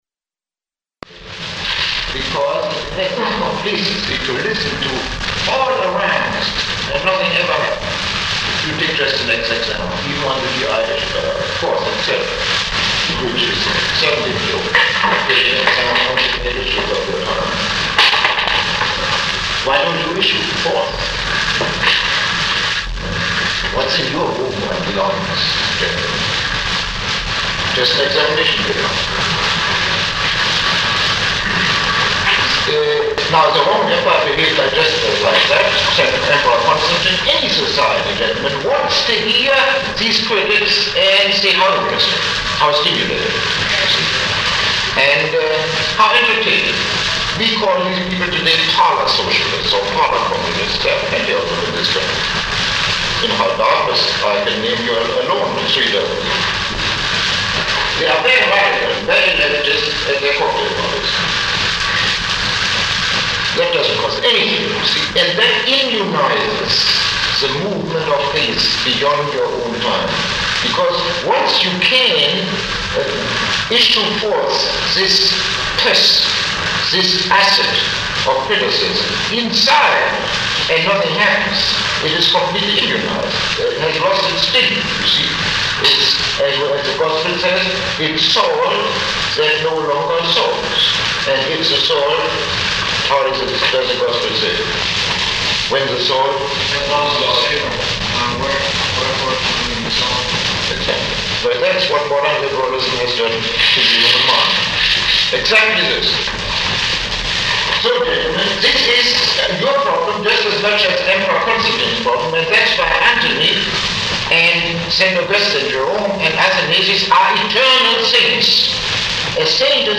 Lecture 3